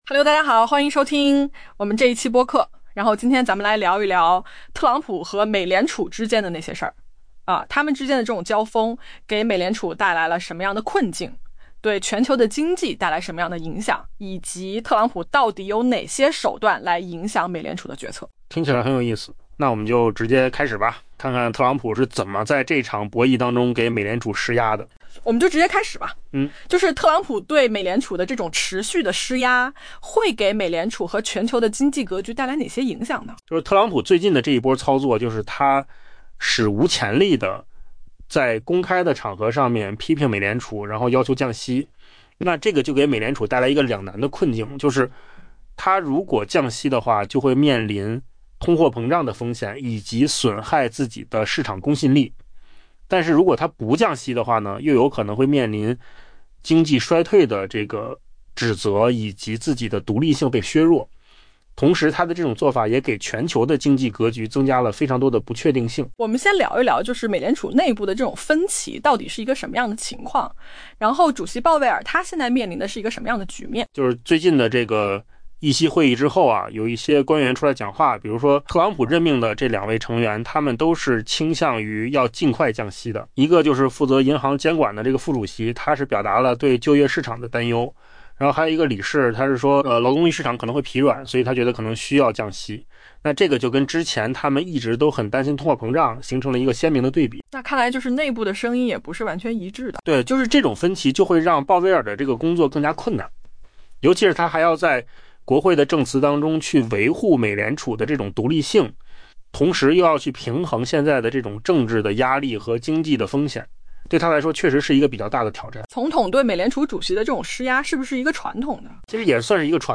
AI播客：换个方式听新闻（音频由扣子空间生成） 下载mp3